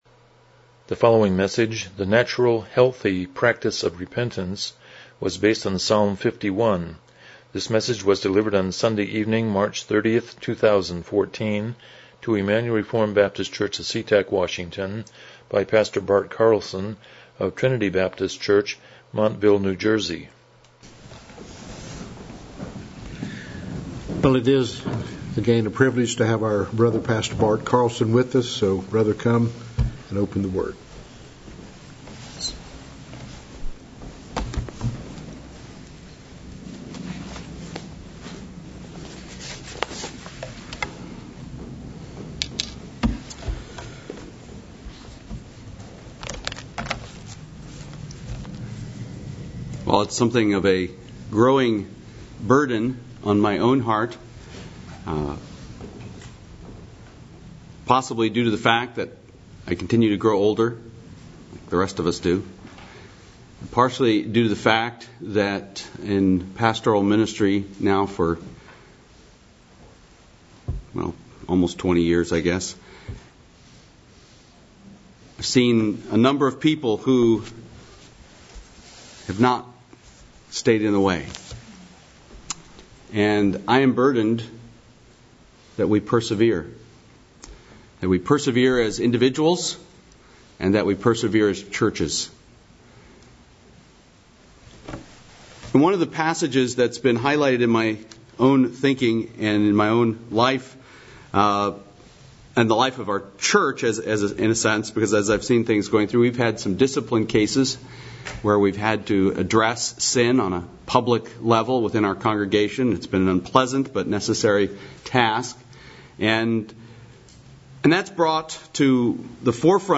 Passage: Psalm 51:1-19 Service Type: Evening Worship « 18 How Does the New Testament Describe the Christian Life?